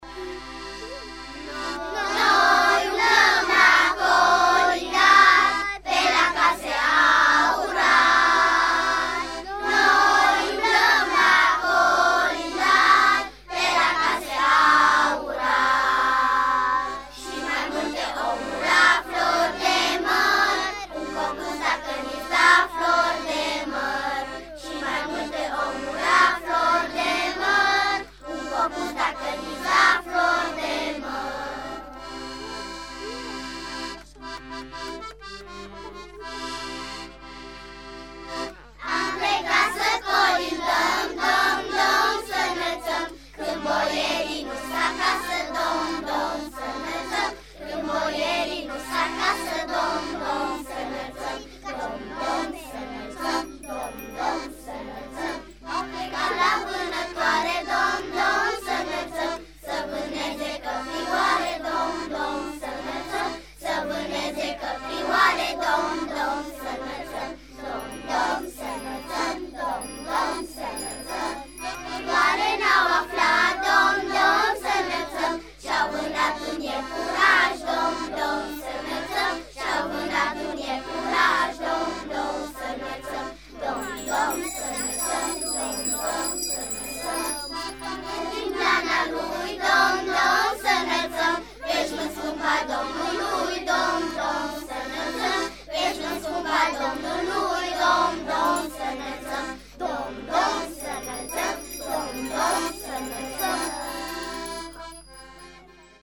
Atmosfera sărbătorilor de iarnă se simte deja pe undele radio. Primii colindători au venit astăzi la sediul Radio Timișoara de pe strada Pestalozzi 14A să ne colinde.
Copiii din clasa pregătitoare A de la Școala Gimnazială nr. 12 din Timișoara formează Corul Clopoțeilor și ne-au demonstrat că adevăratele colinde le sunt la fel de dragi ca și nouă.
Colinda-clasa-pregatitoare-la-Radio-Timisoara.mp3